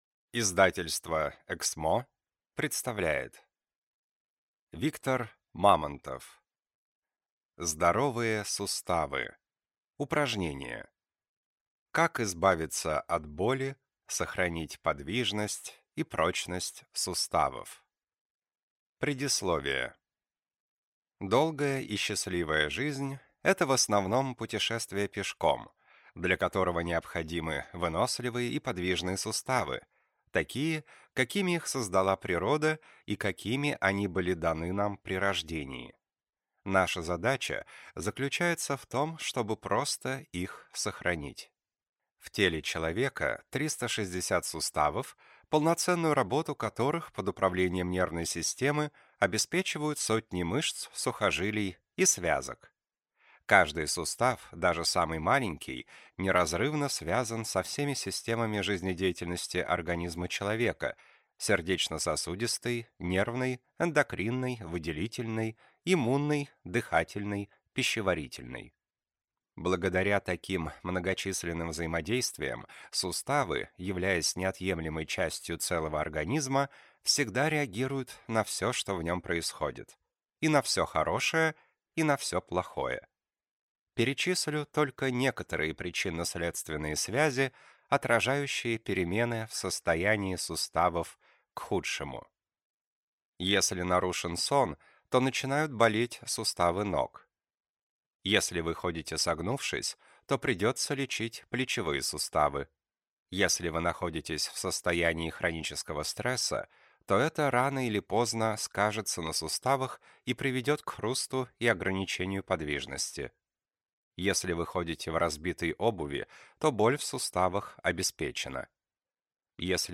Аудиокнига Упражнения для суставов. Как избавиться от боли, сохранить подвижность, предотвратить переломы | Библиотека аудиокниг